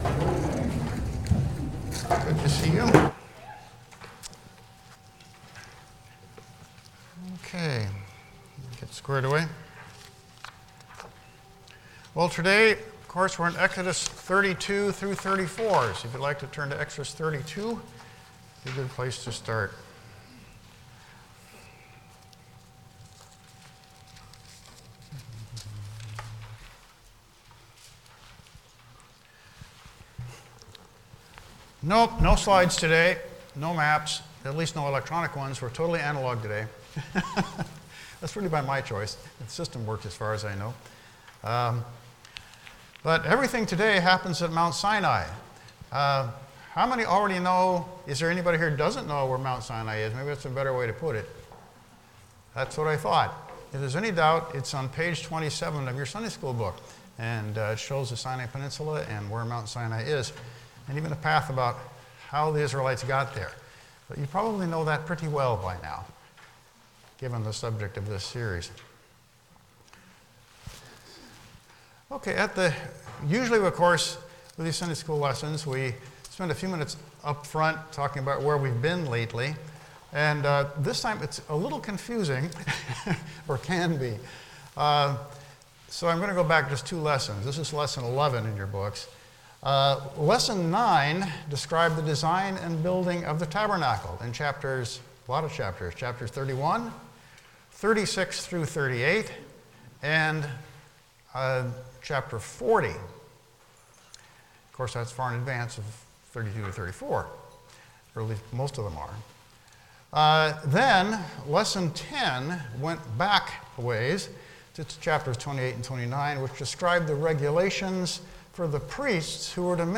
The Exodus Passage: Exodus 32-34 Service Type: Sunday School « The Interludes in the Revelation No. 4